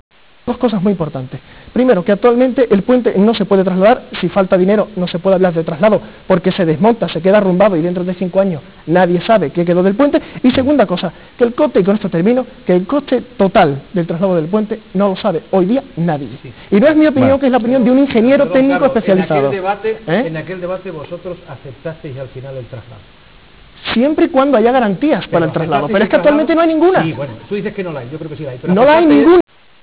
A finales de 1997 se organizó un debate televisivo en Onda Giralda Televisión, en el cual se mostraron las diferentes posturas existentes sobre el Puente de Alfonso XIII.